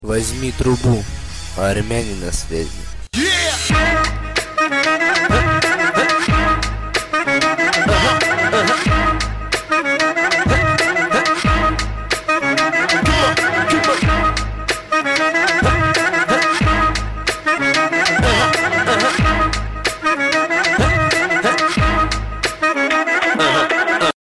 веселые
смешные